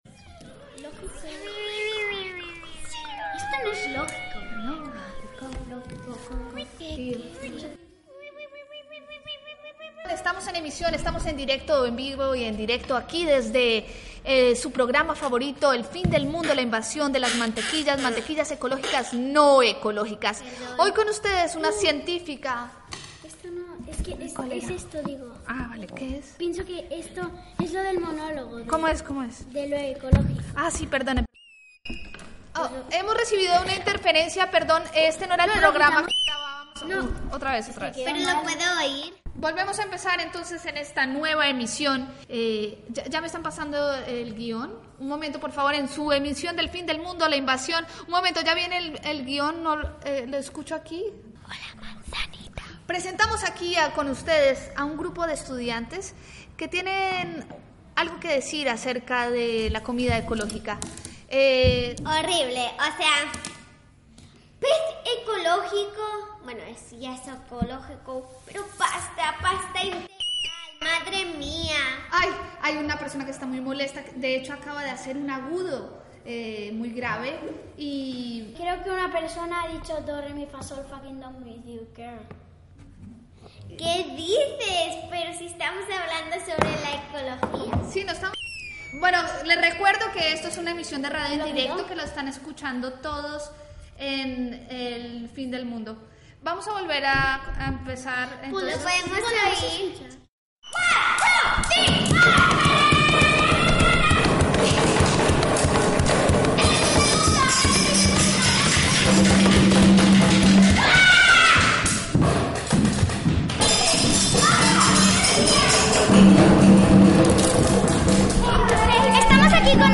"Lo eco no es lógico". Ficció sonora basat en la interferència amb el programa "El fin del mundo la invasión de las matequillas ecológicas"
Ficció